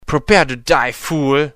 Englische Sprecher (m)